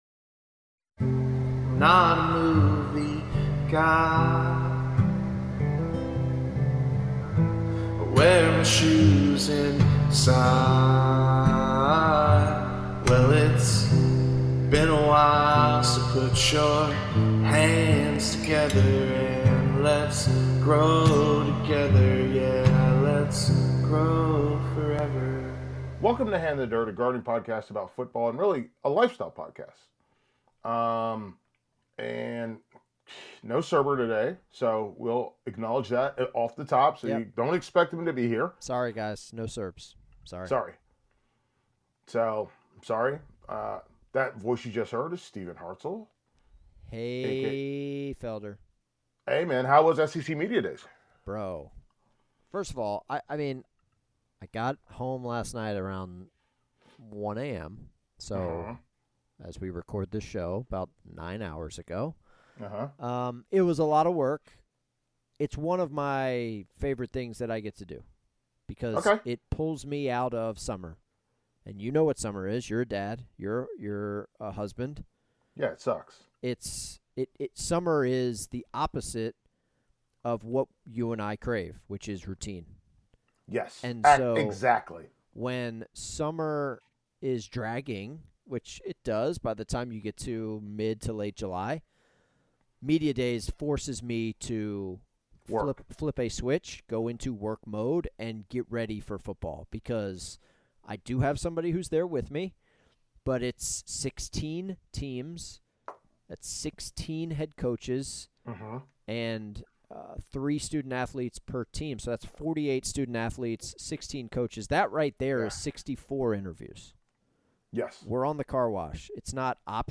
A gardening podcast about football, and really it's a lifestyle podcast. The episode with 18 voicemails